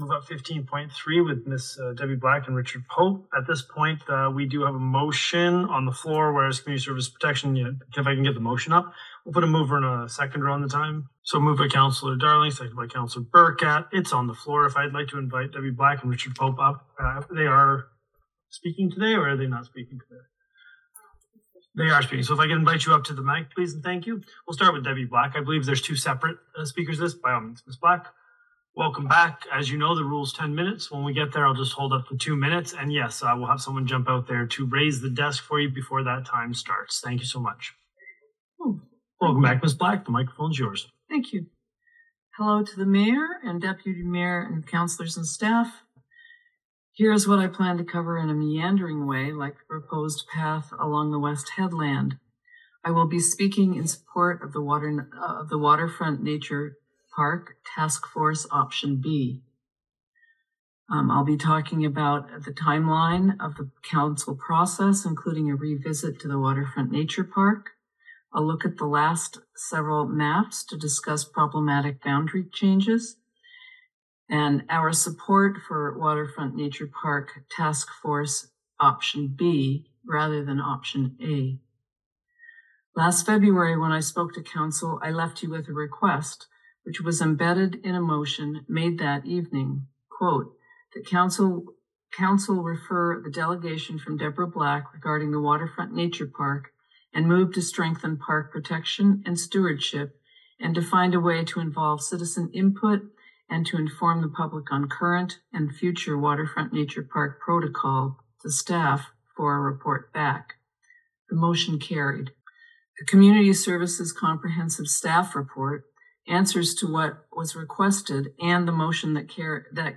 Cobourg council narrowly rejected a proposal to create a waterfront task force to oversee changes to the Waterfront Nature Park on the west beach and headlands at its recent regular council meeting on May 28.